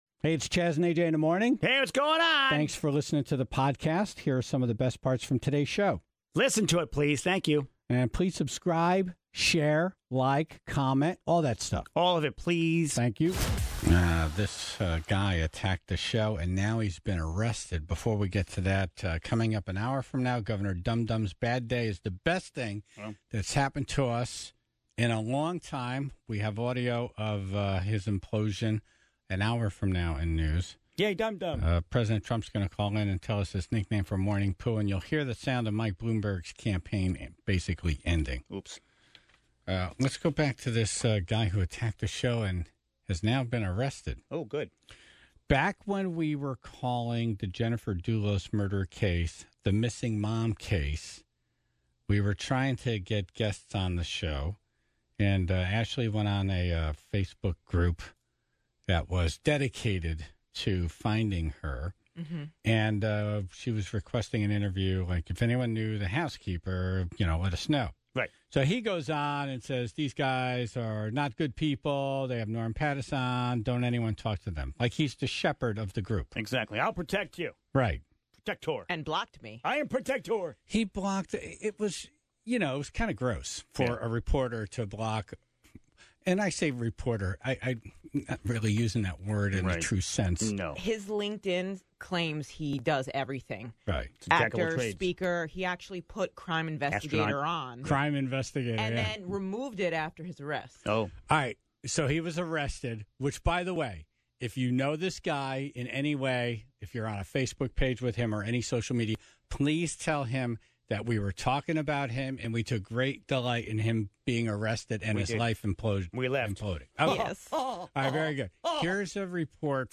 President Trump calls in to talk about the debate, how he celebrates Presidents' Day, and is once again surprised on the phone by Bernie Sanders and Michael Bloomberg (16:16) Governor Lamont sounds defeated and angry while announcing the tolls proposal has died because of inaction (20:42)